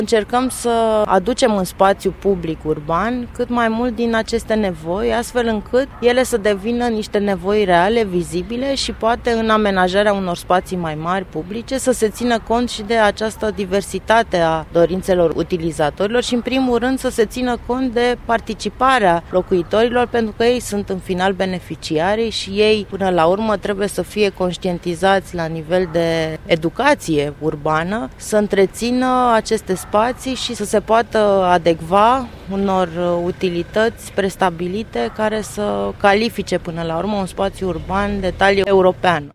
doctor în urbanism